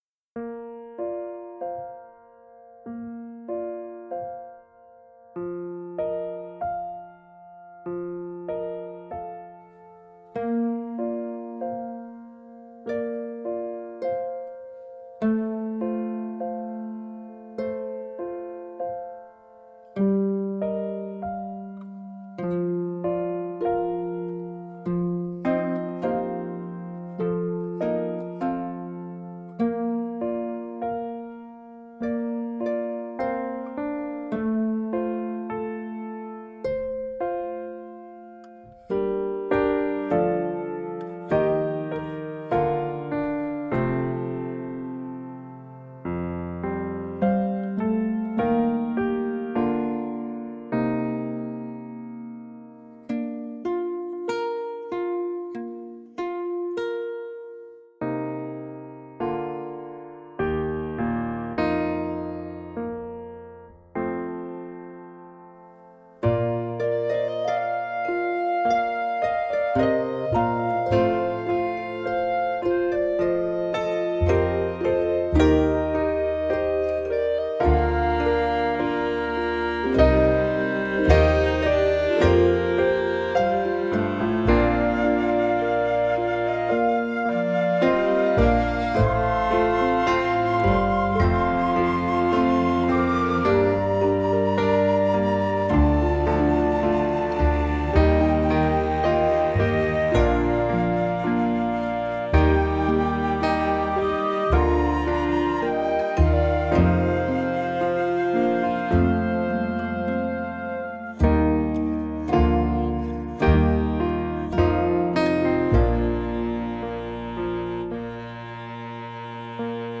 Genre: Comédie musicale.